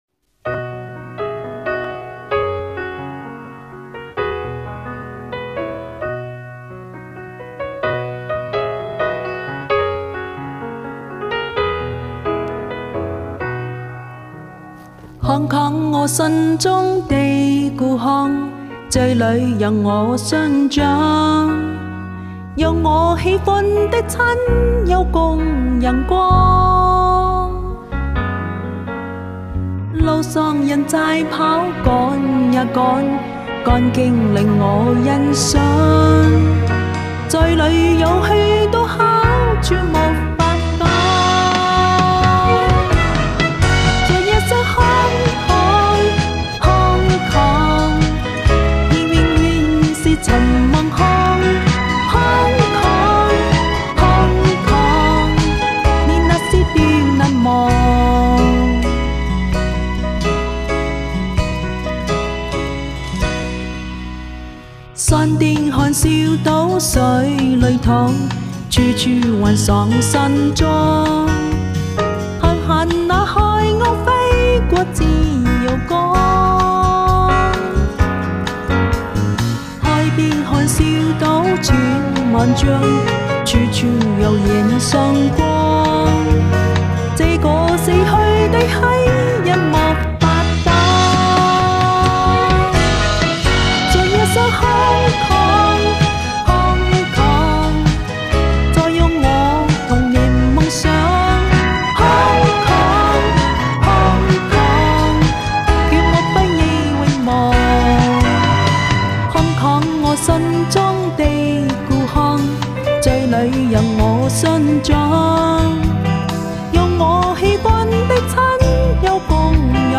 地道，自然 圆润！
你唱的这首我还是投次听呢，唱得真好听👍你的发音吐字很清晰，音色也很好听！